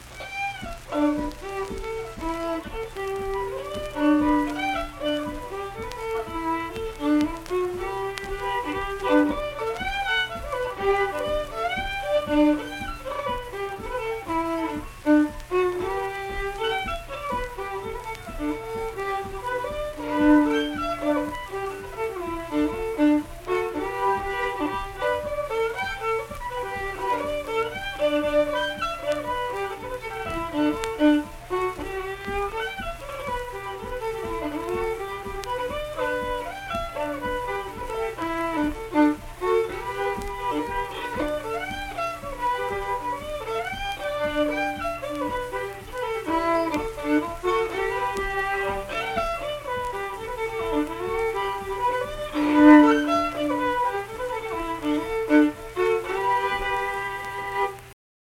Unaccompanied fiddle performance
Instrumental Music
Fiddle
Middlebourne (W. Va.), Tyler County (W. Va.)